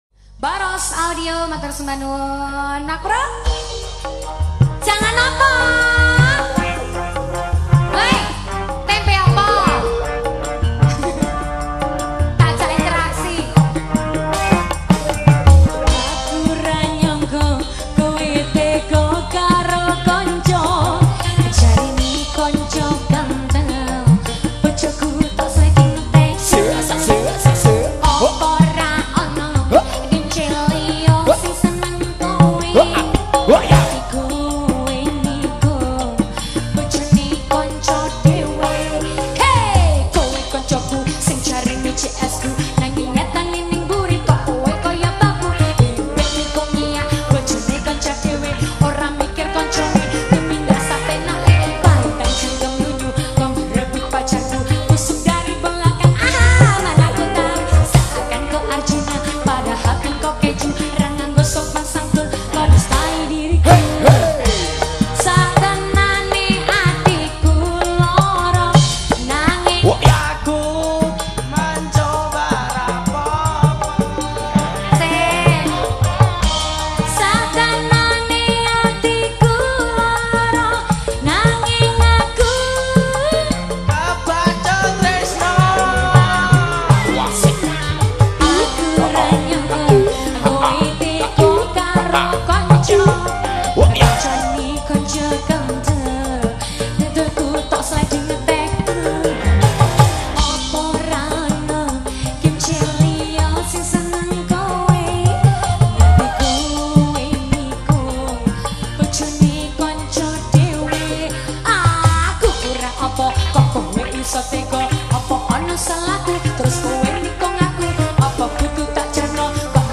diiringi oleh group orkes melayu dangdut koplo